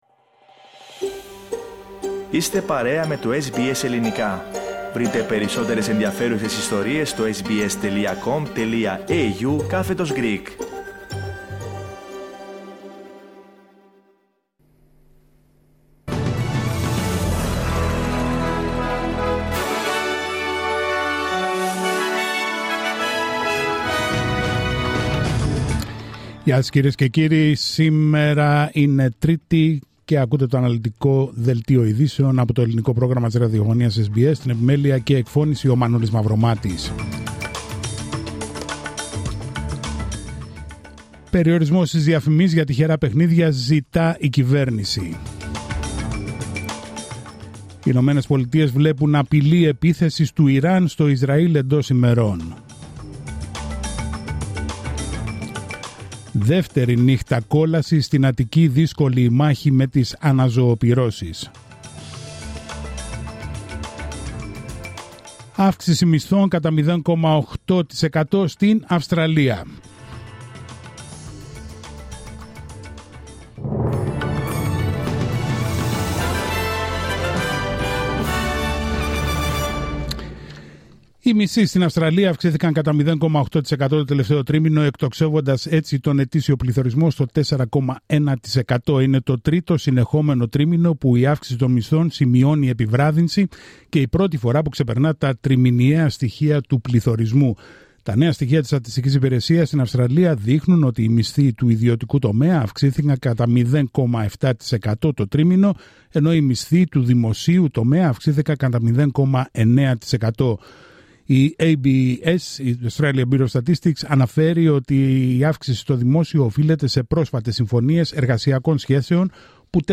Δελτίο ειδήσεων Τρίτη 13 Αυγουστου 2024